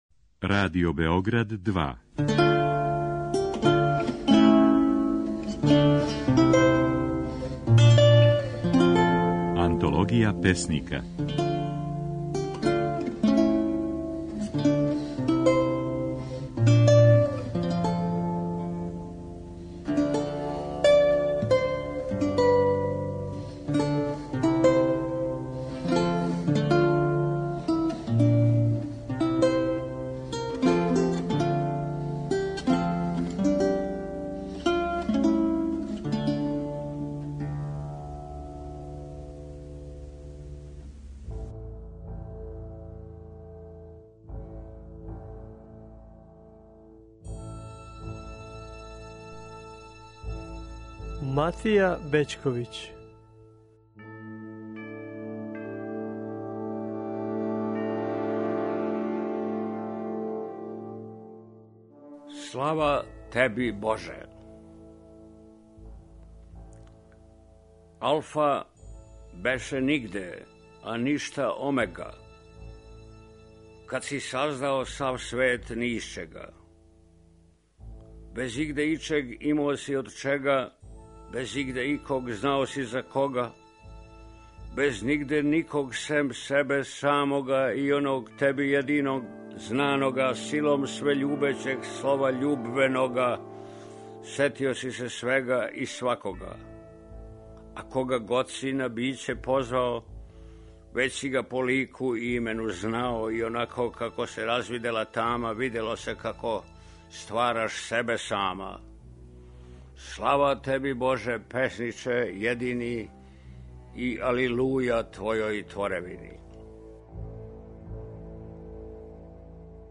Oд 23. до 27. јануара, Матија Бећковић говoри поезију из књиге ТРИ ПОЕМЕ: 'Господе помилуј', 'Учини ми љубав' и 'Слава теби Боже'.
Емитујемо снимке на којима своје стихове говоре наши познати песници